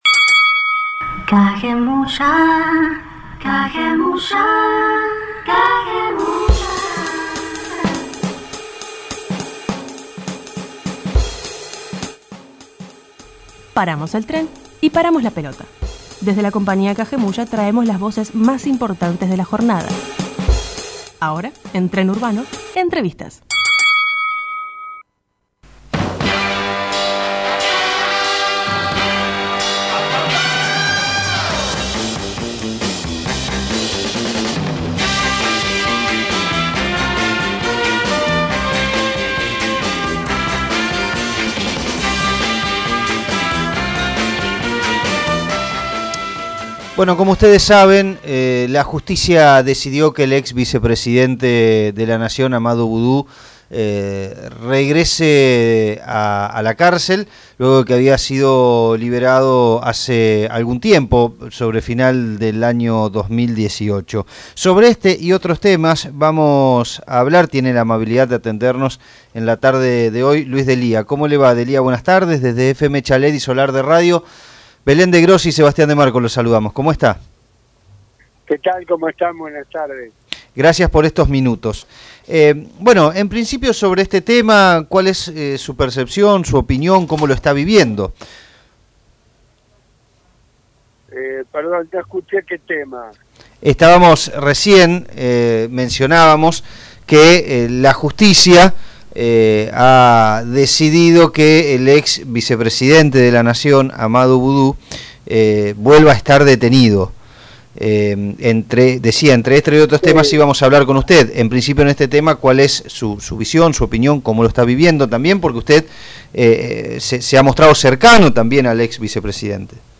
Radio comunitaria 100.9